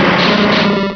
Cri de Smogo dans Pokémon Rubis et Saphir.